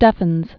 (stĕfənz), (Joseph) Lincoln 1866-1936.